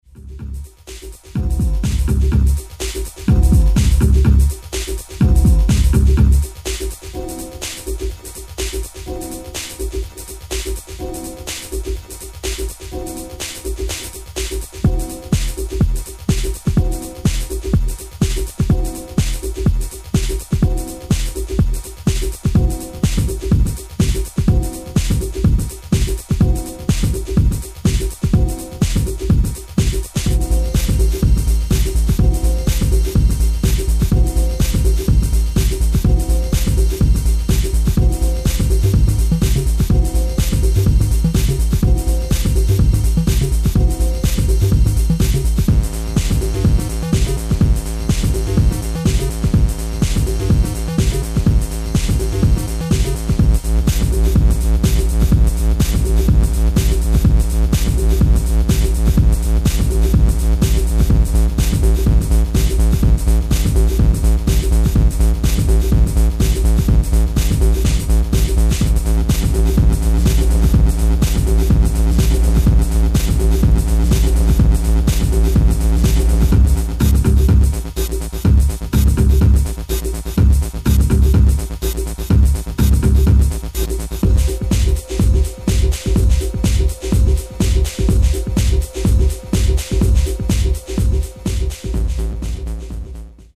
goes electronic
High tech jazz mate... more... 1 a 2 b Techno